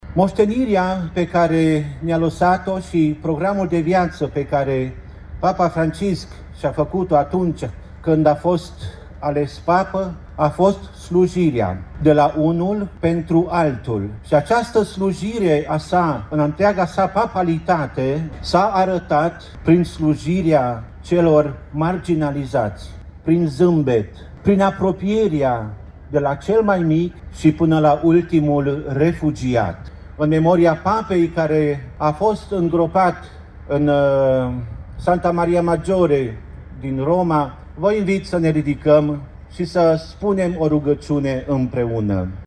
Cu această ocazie a fost păstrat un moment de reculegere în memoria Papei Francisc.